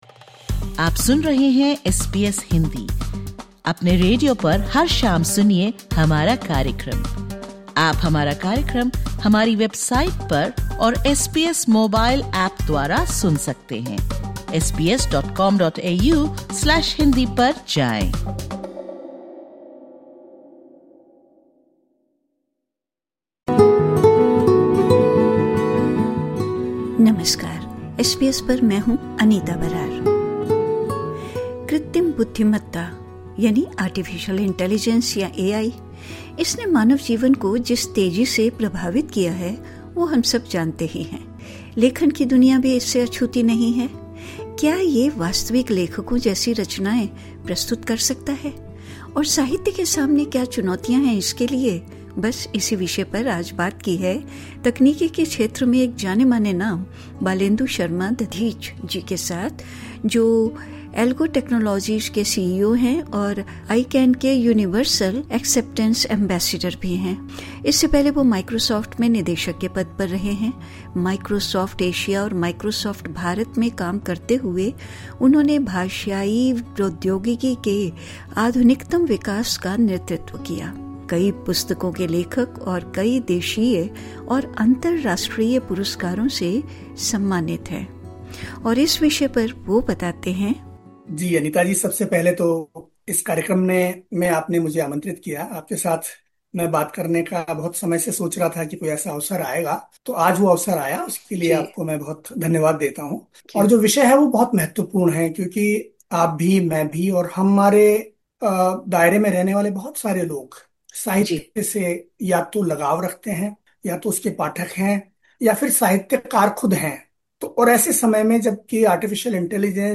एसबीएस हिन्दी से बातचीत करते हुये